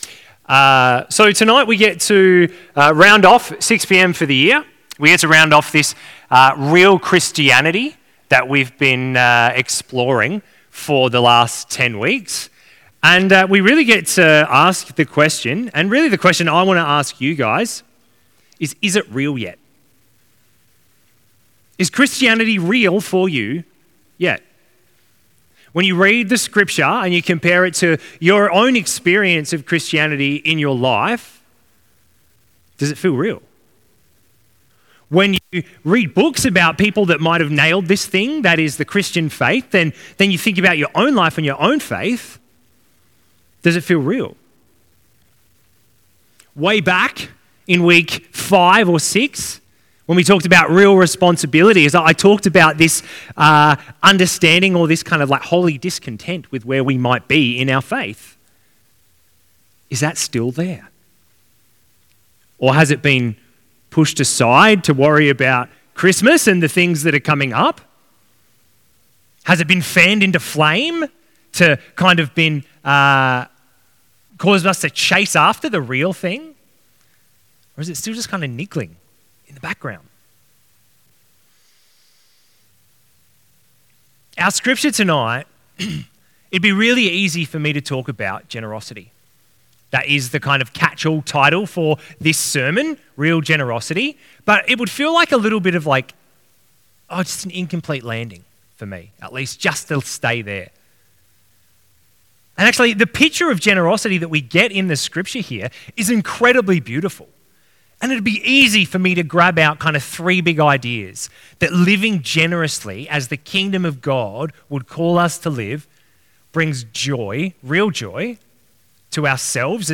Sermons | St Hilary's Anglican Church